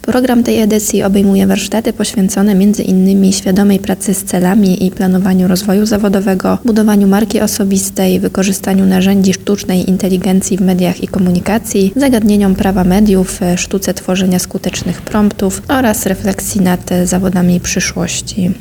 O szczegółach tego wydarzenia mówi